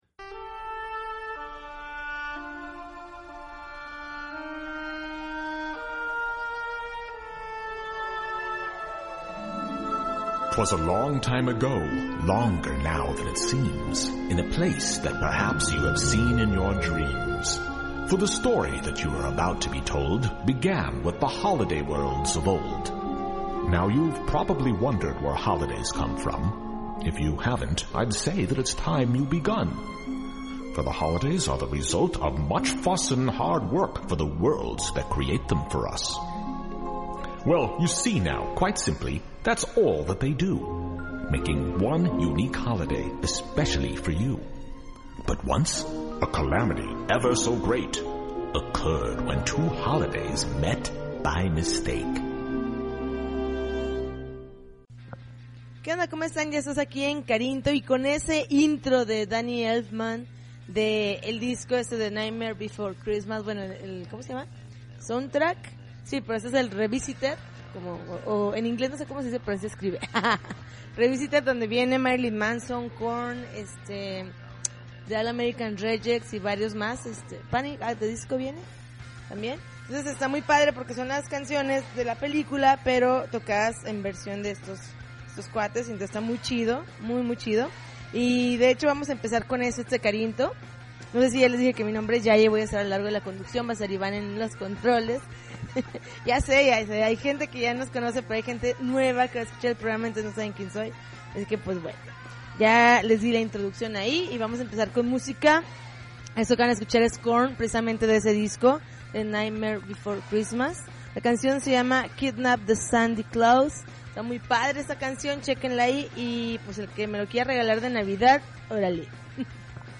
January 11, 2009Podcast, Punk Rock Alternativo